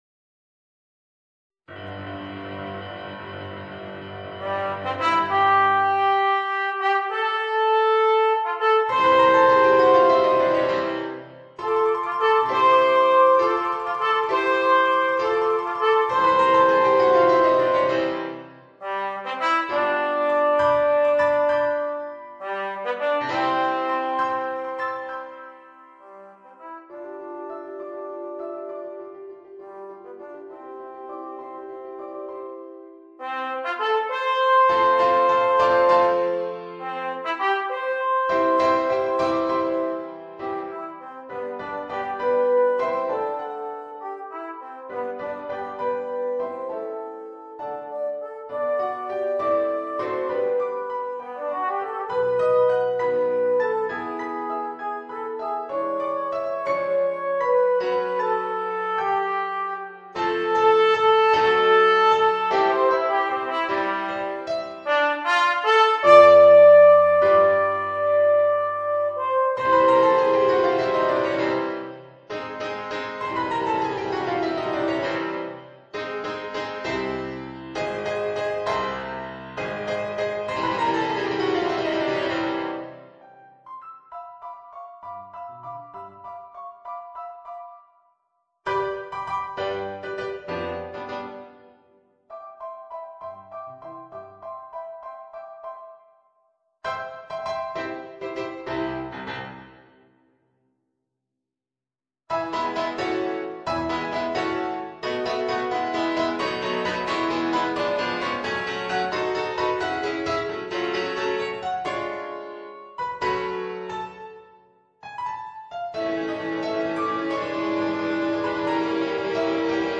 Voicing: Alto Trombone and Piano